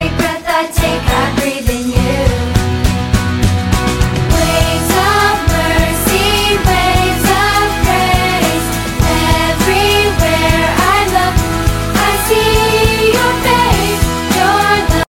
Rip sound effects free download